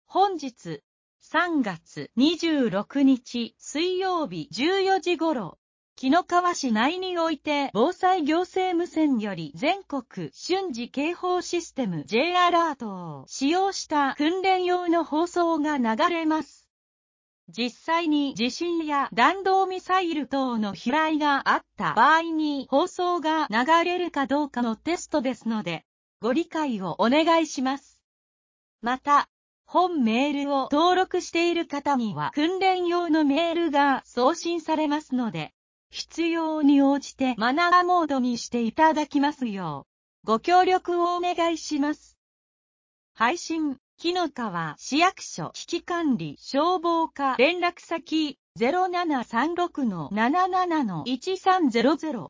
「即時音声合成」